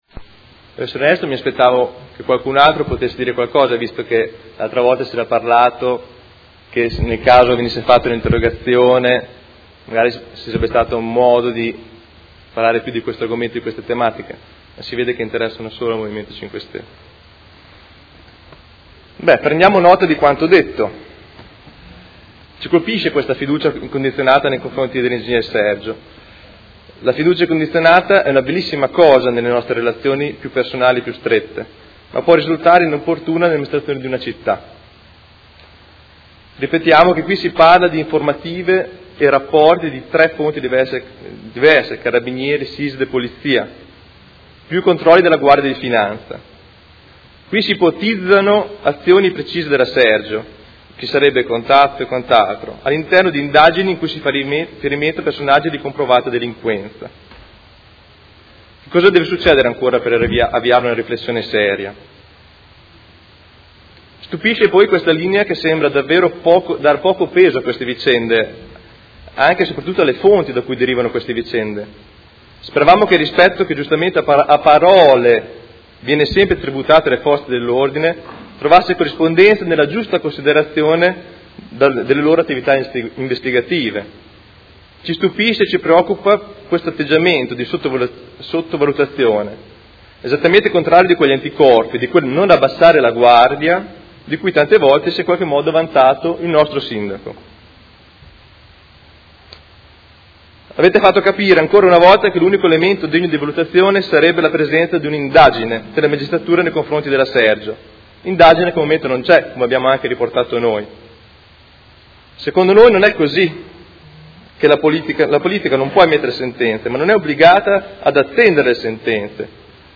Seduta del 16/06/2016. Interrogazione del Gruppo Movimento Cinque Stelle avente per oggetto: Valutazioni recenti informazioni sulla dirigente Ing.
Replica